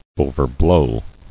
[o·ver·blow]